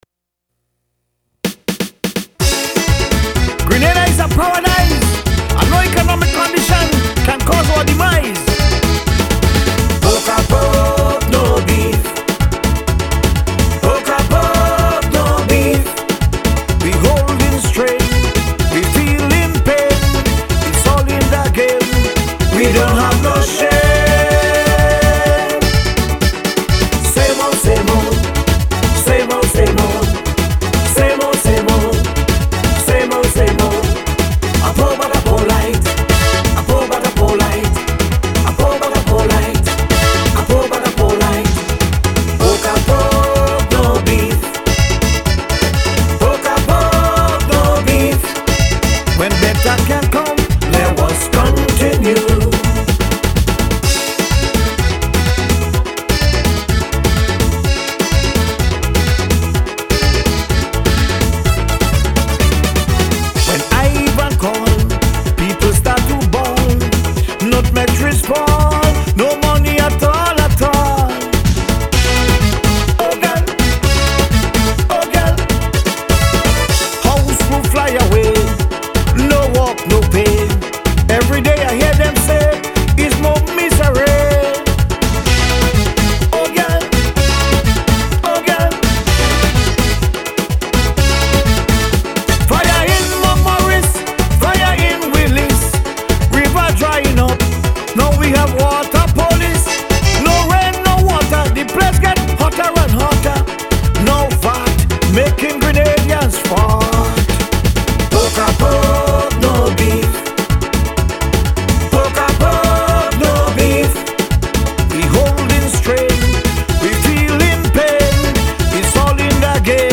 Calypso (extempo)